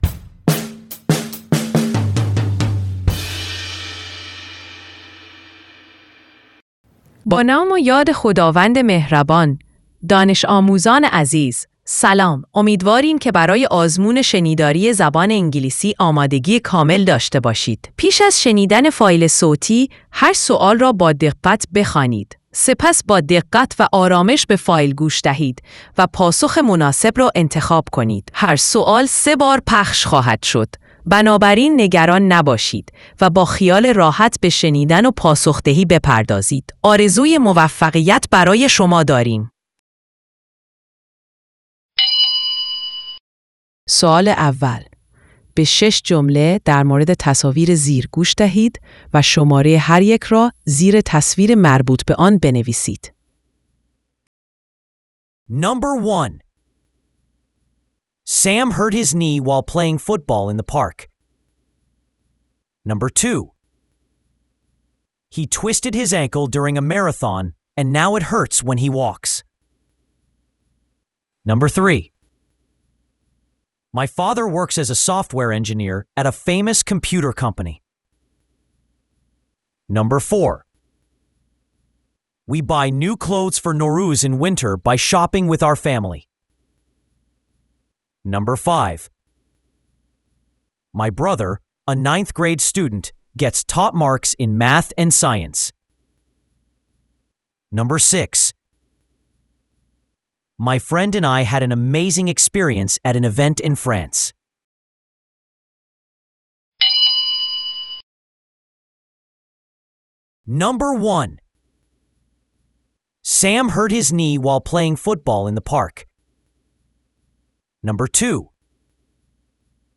دانلود فایل صوتی آزمون شنیداری (رایگان)